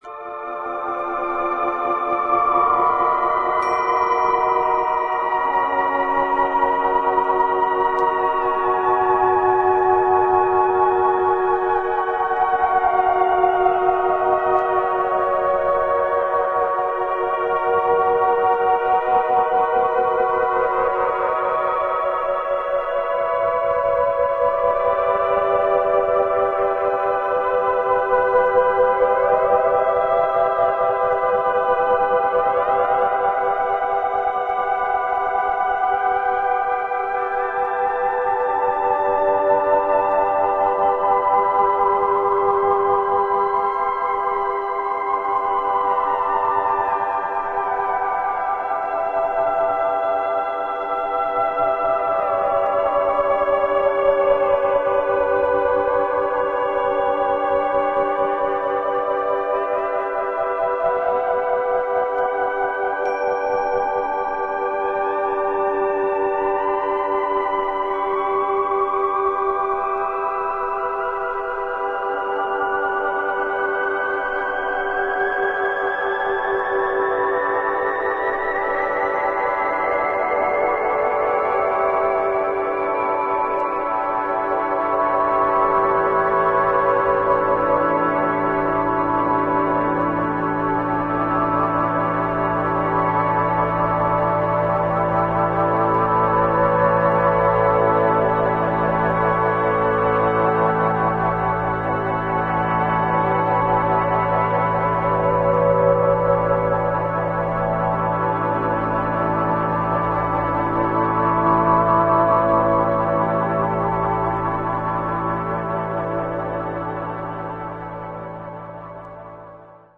MEDIA：VG＋ B面頭に4回プツッというノイズが入ります。